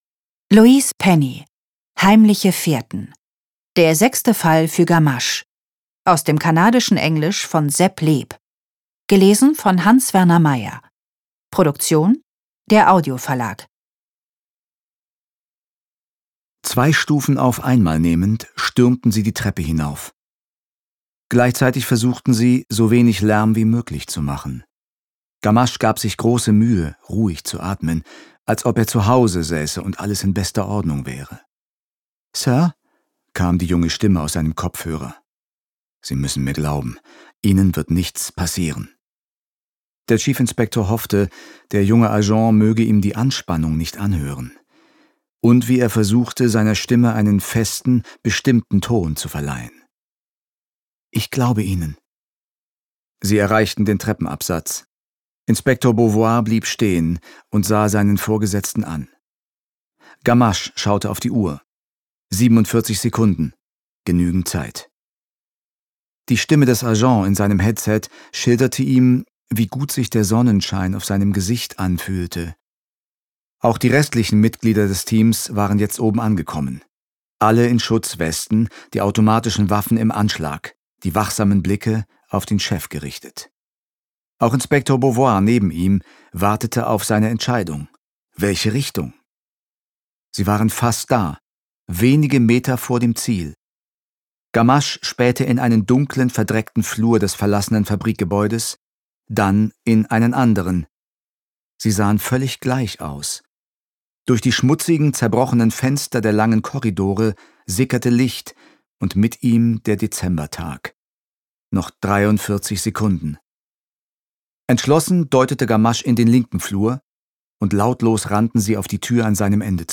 Hans-Werner Meyer (Sprecher)
Ungekürzte Lesung